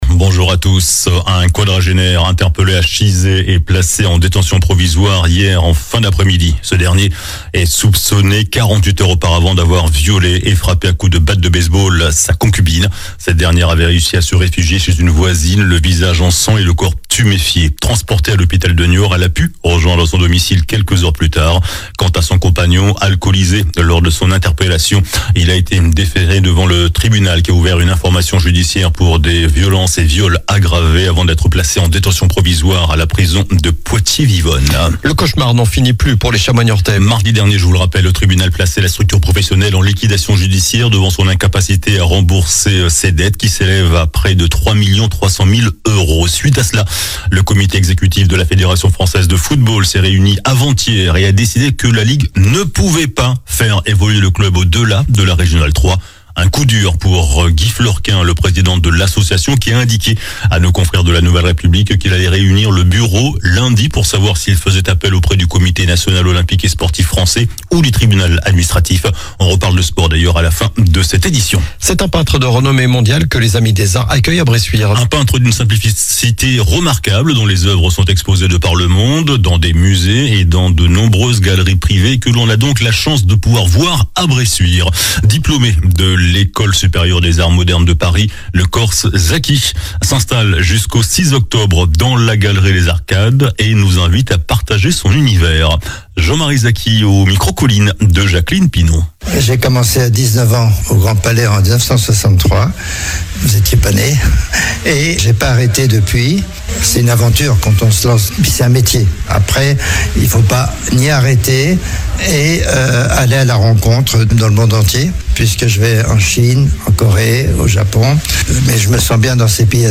JOURNAL DU SAMEDI 14 SEPTEMBRE